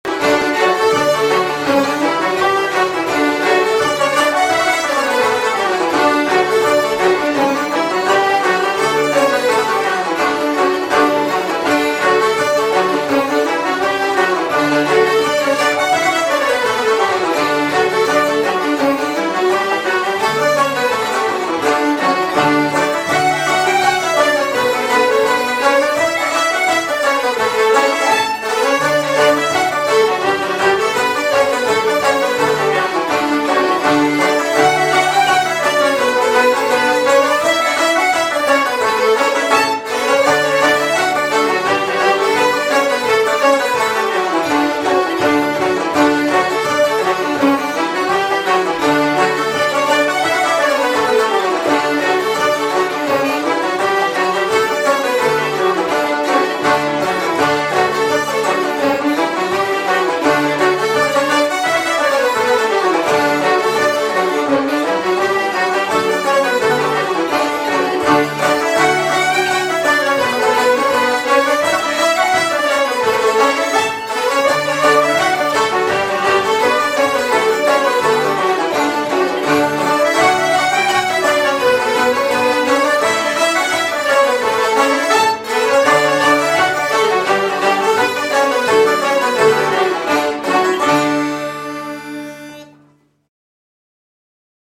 Irish traditional music